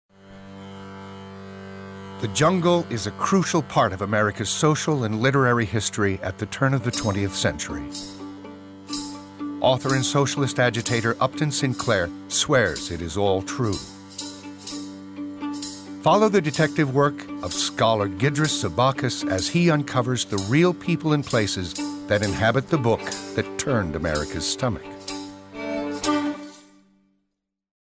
narration : men